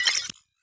Hi Tech Alert 14.wav